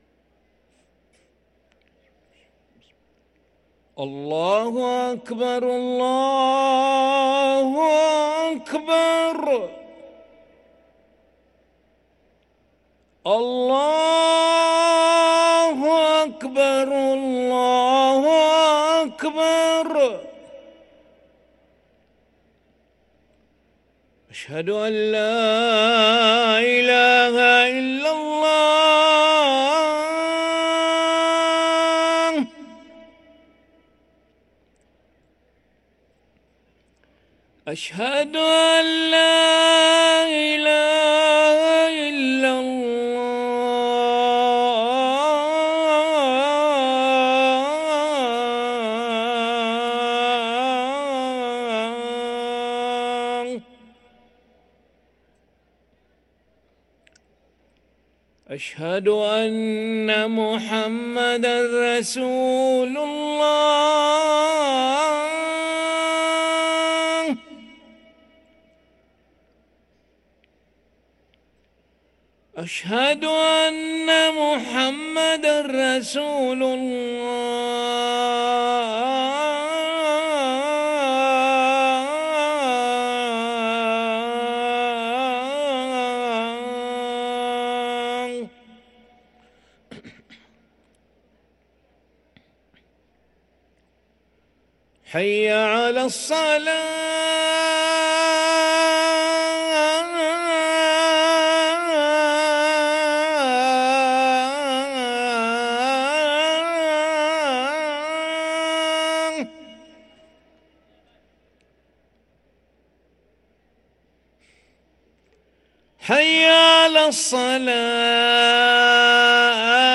أذان العشاء للمؤذن علي أحمد ملا الأحد 8 ذو القعدة 1444هـ > ١٤٤٤ 🕋 > ركن الأذان 🕋 > المزيد - تلاوات الحرمين